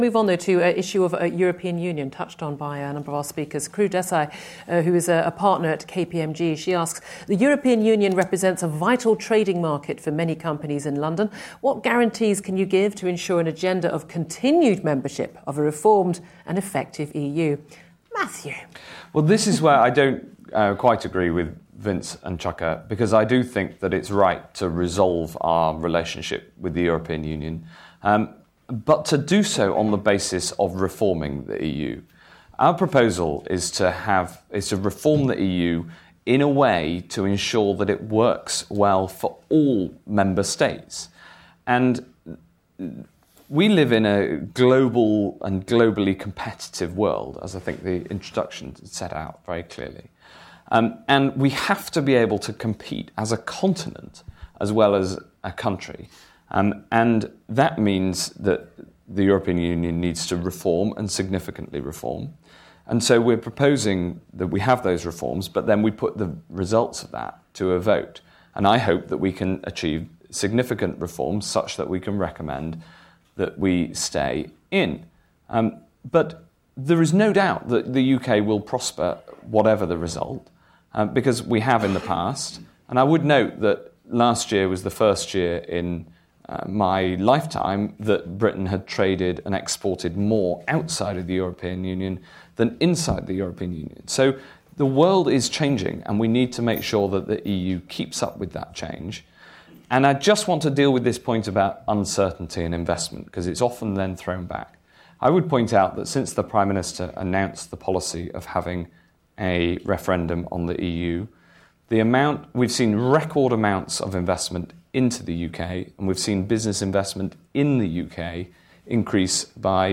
Listen to Matt Hancock MP, Chuka Umunna MP and Vince Cable MP talk about a potential EU Referendum and its impact on business.